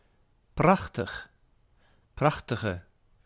Ääntäminen
US : IPA : [ɡɹeɪt]